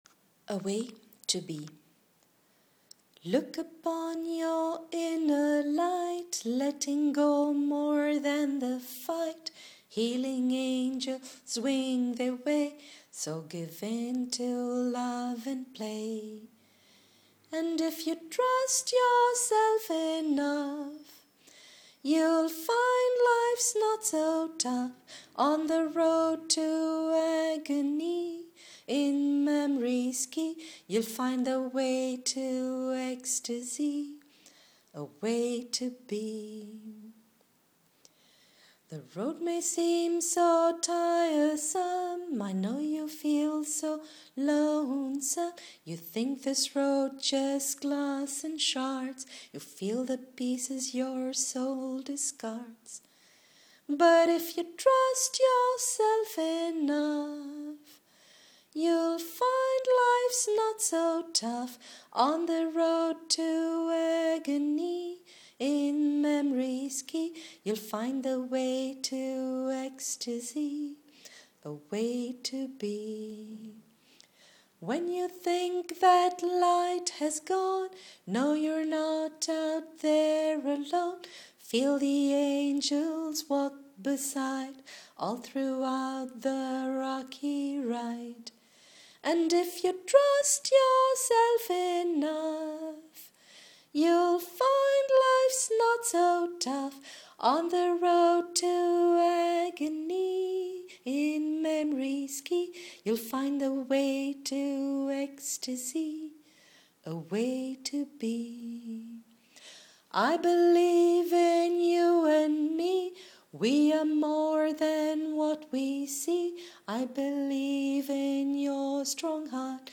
Bear with me because I am not a singer but would really like to get this melody and the lyrics out there because it made me feel good so I thought it might help others feel good too
This was recorded as a memo on my phone because my usual camcorder was not working.